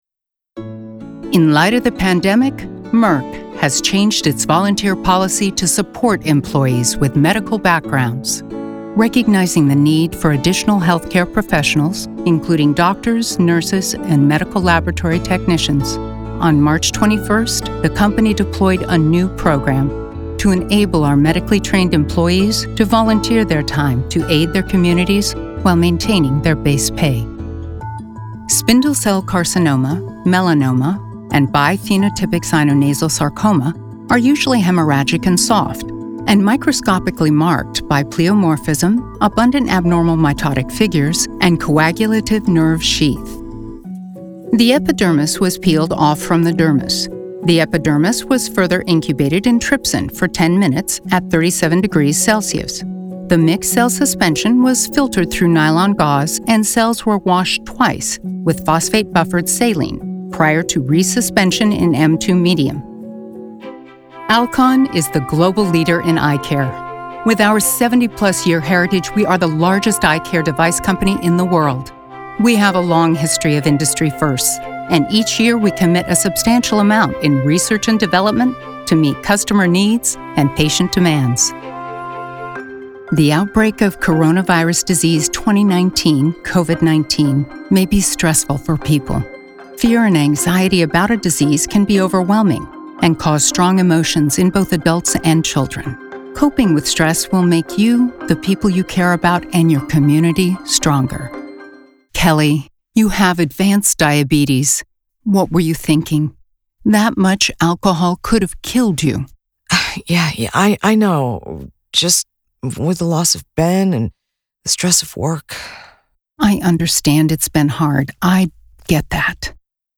Medical Narration Demo
Neutral US, RP-British, German, & French
Middle Aged